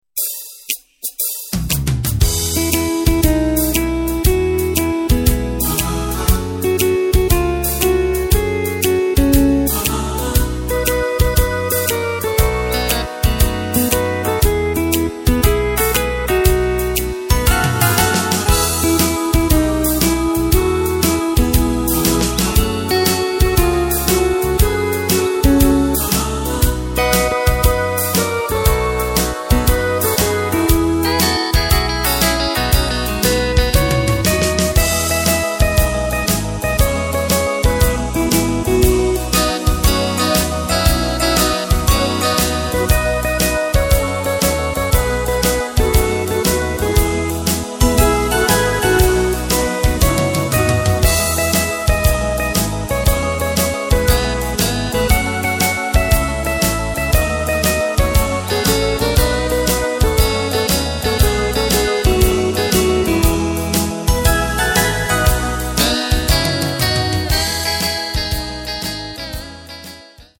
Takt:          4/4
Tempo:         118.00
Tonart:            E
Schlager aus dem Jahr 2011!